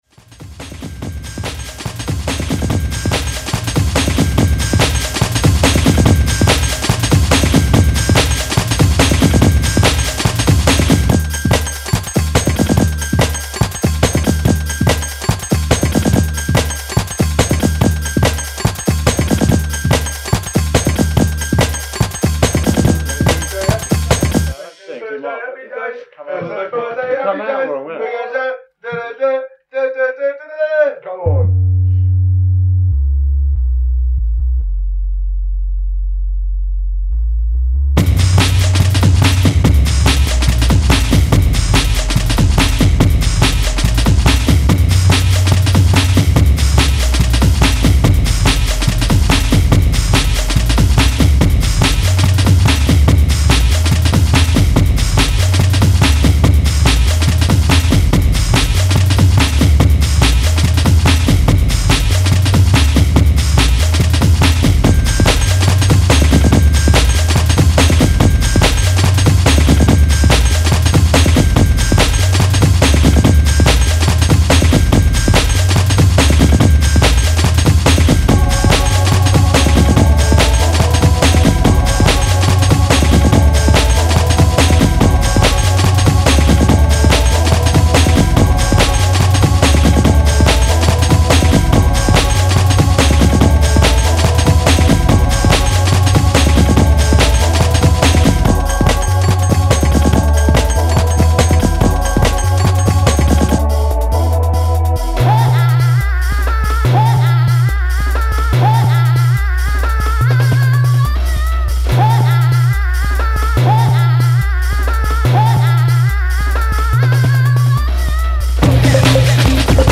high-energy, sample-laden tracks